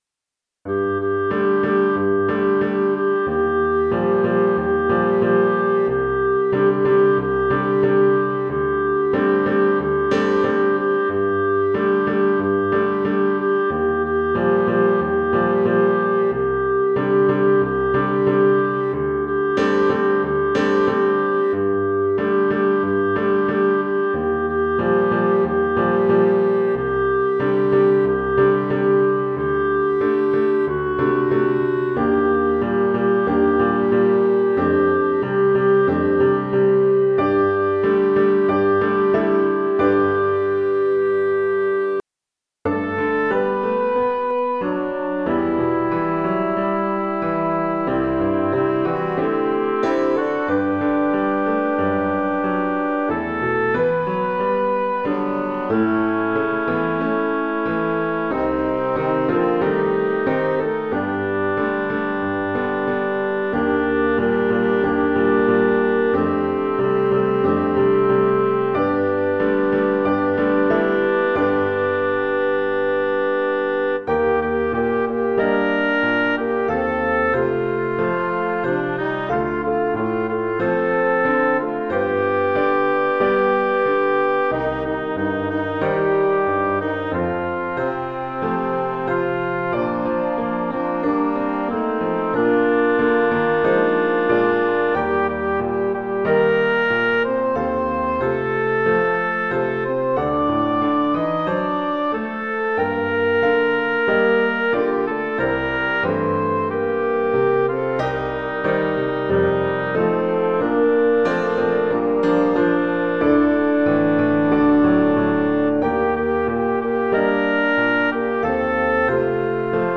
ALL   Instrumental | Downloadable